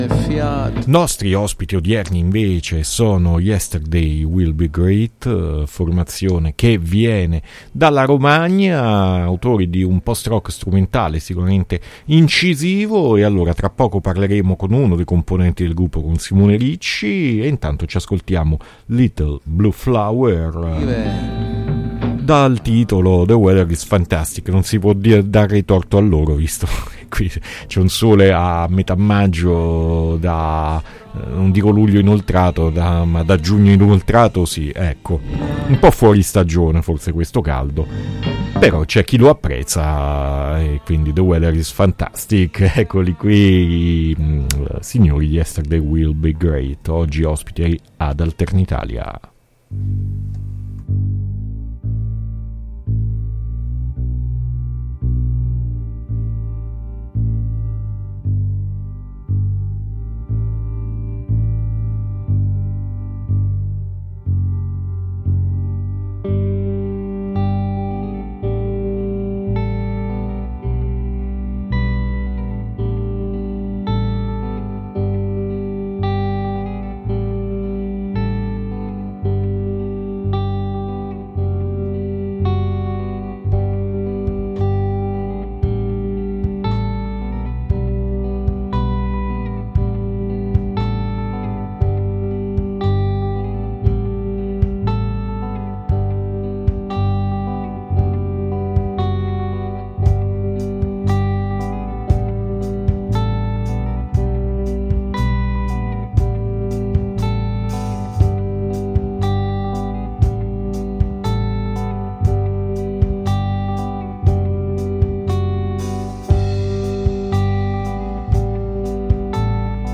intenso e ben arrangiato post rock strumentale